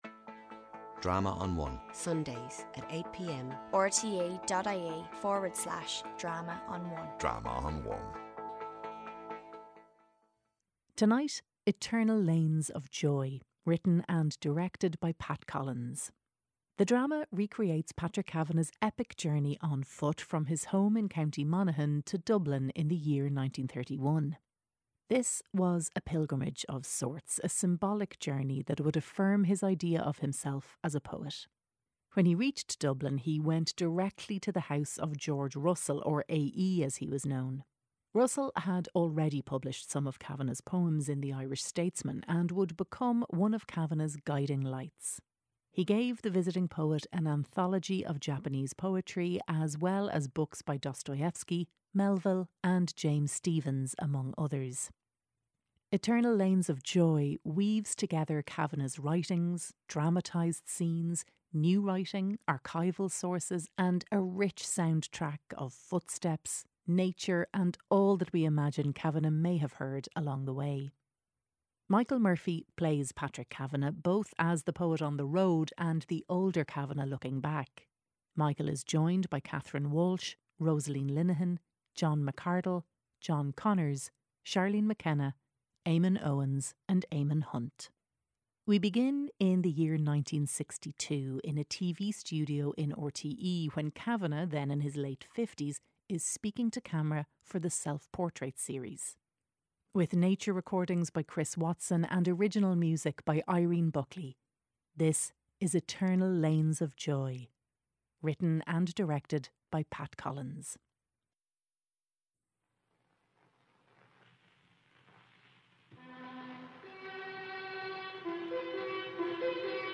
RTÉ Radio Drama's audio theatre department has for decades proudly brought audiences the very best dramatic writing and performances for radio from Ireland.